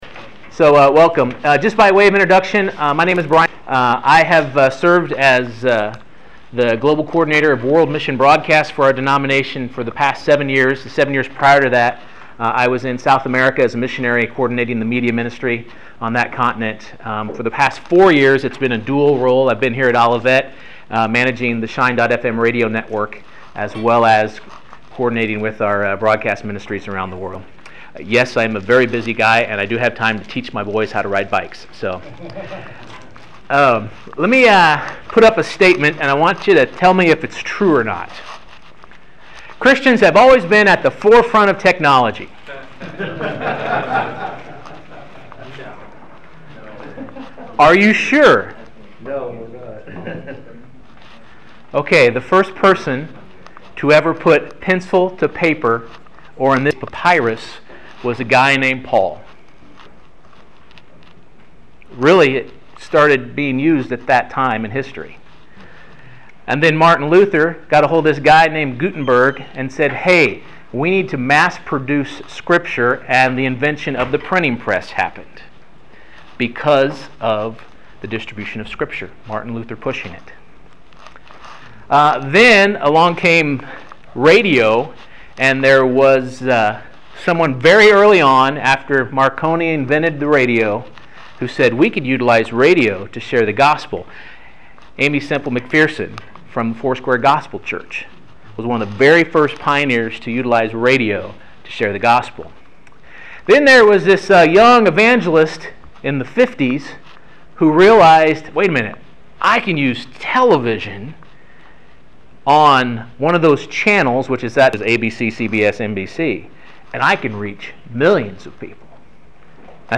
How does this affect the local church? This workshop looks at ways to implement a simple media strategy to impact your community